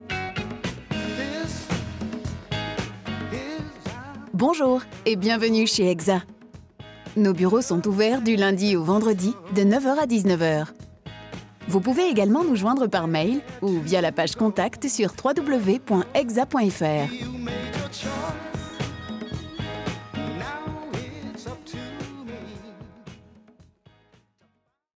Young, Playful, Commercial, Natural, Versatile
Telephony